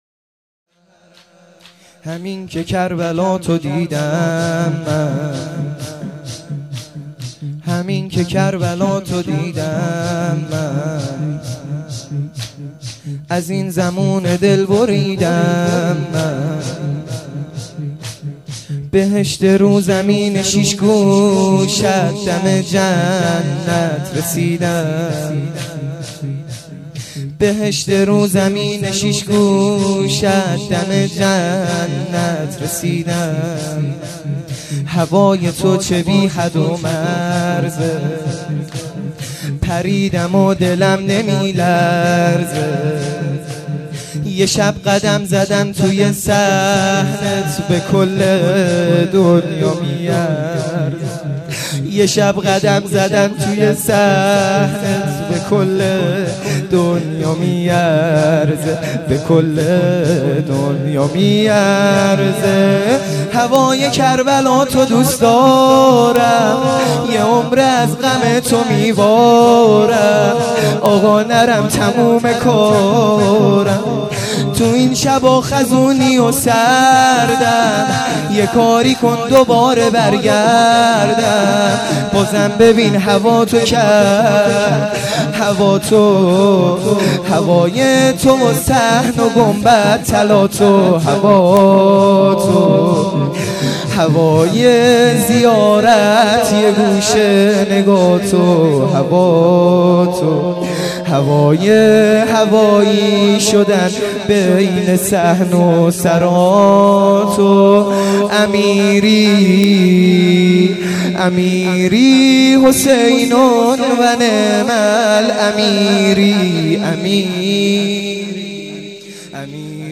شور | همینکه کربلاتو دیدم من